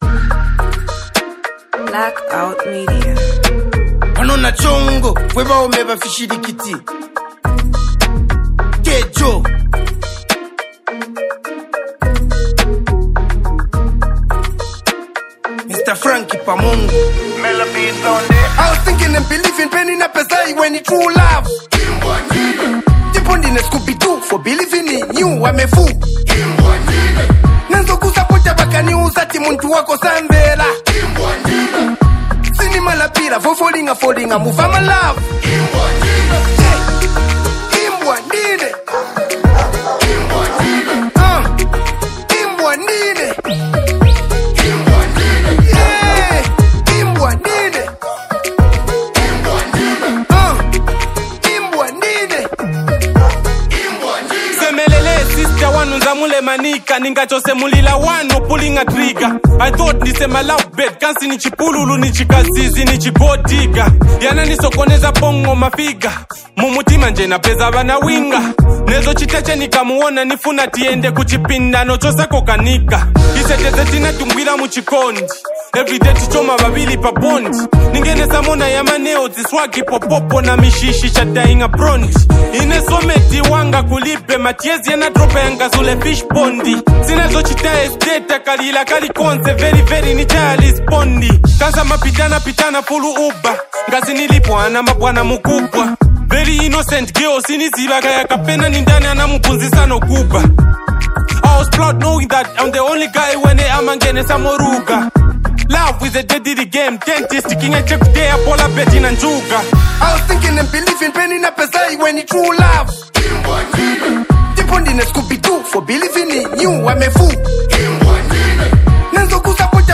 ” and it’s a track full of attitude and swagger.
smooth delivery
catchy hook
hard-hitting track